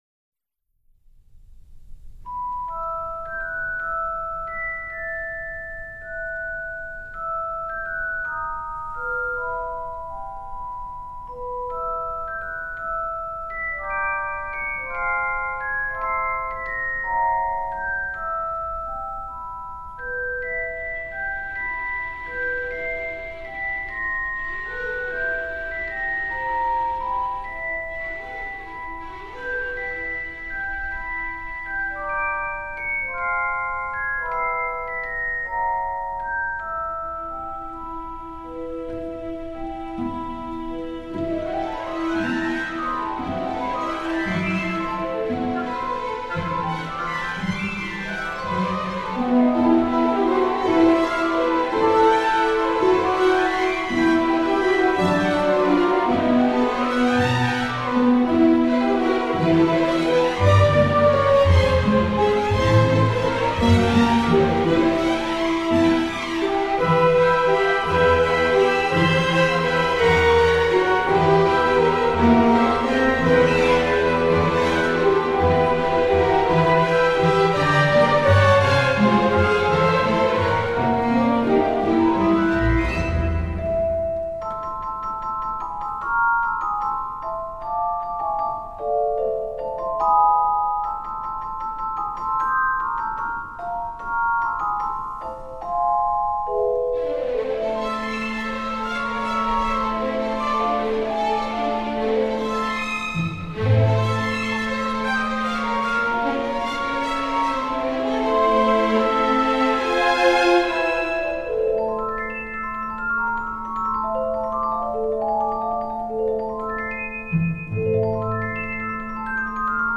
• Качество: 192, Stereo
без слов
нарастающие
инструментальные
волшебные
оркестр
вальс
интригующие
загадочные